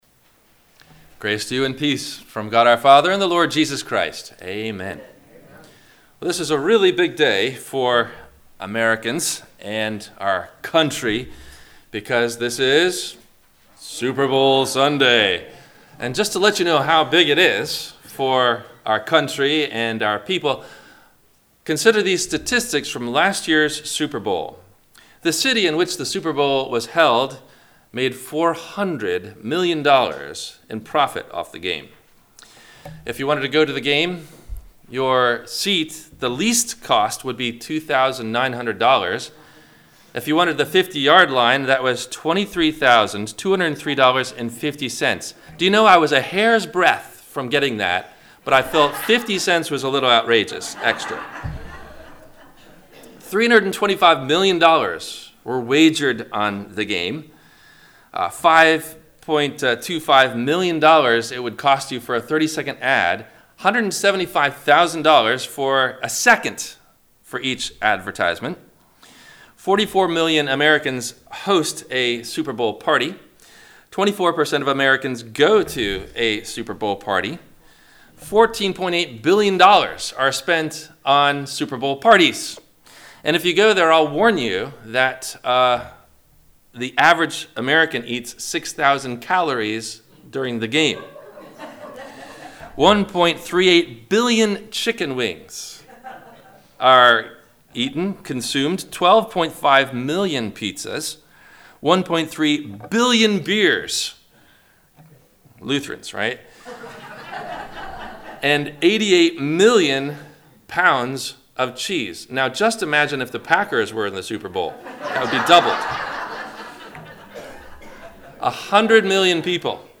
Superbowl vs Super Church - Sermon - February 02 2020 - Christ Lutheran Cape Canaveral